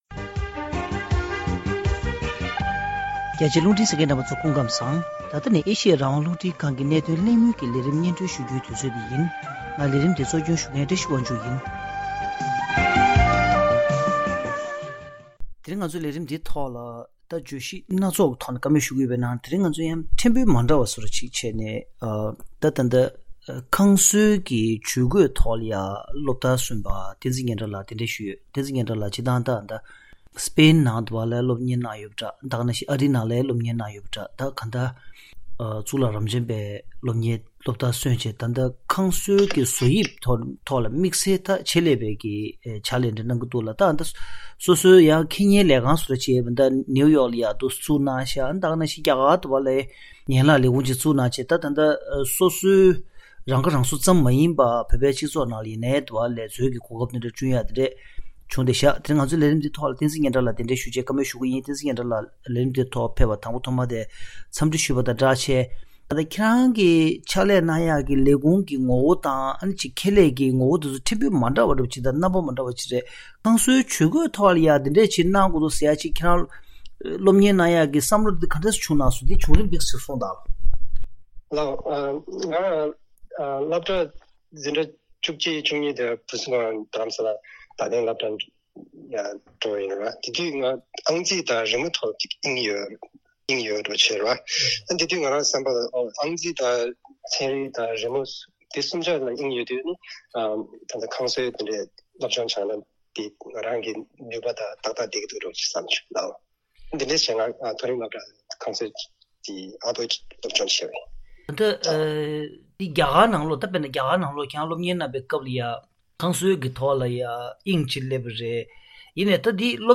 ལྷན་གླེང་མོལ།